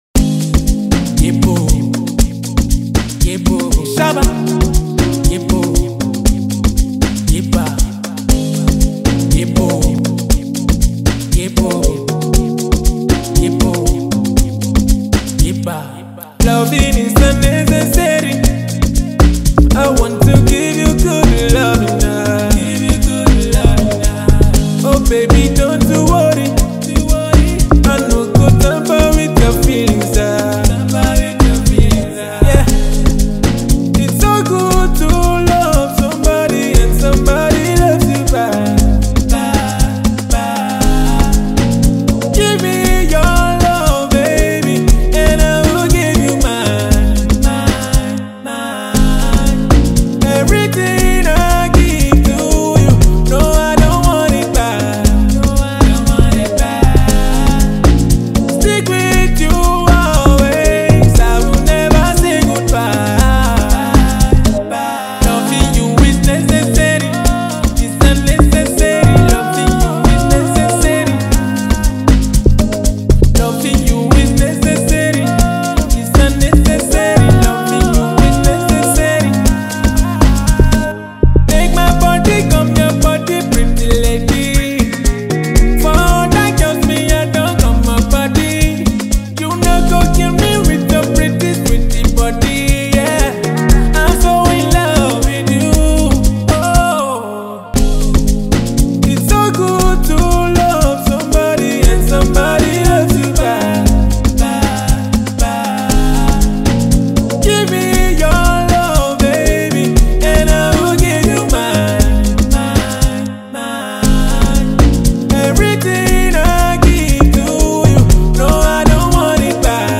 Nigerian talented singer and songwriter